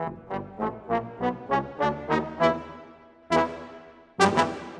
Rock-Pop 20 Trombone _ Tuba 02.wav